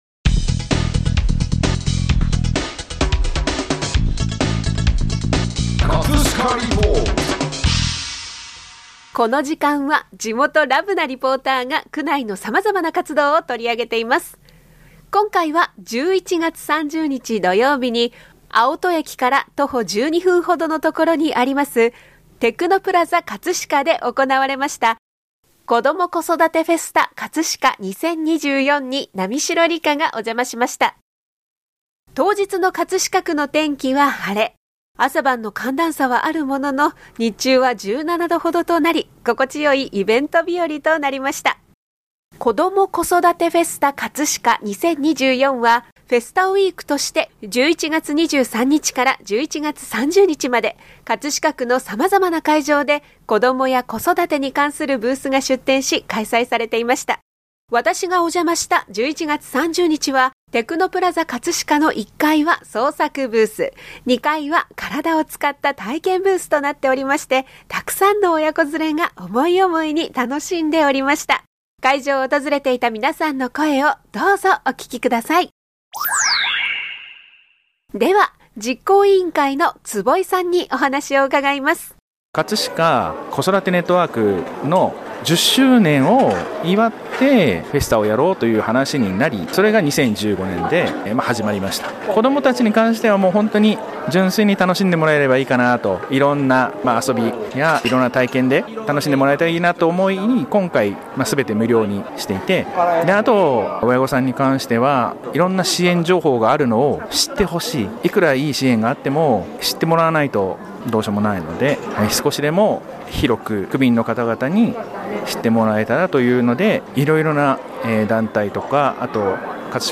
【葛飾リポート】 葛飾リポートでは、区内の様々な活動を取り上げています。
11月30日のイベントでは、テクノプラザかつしかの1階は創作ブース、2階は体を使った体験ブースとなっており、沢山の親子連れが思い思いに楽しんでおりました。 会場を訪れていた皆さんの声をどうぞお聴き下さい！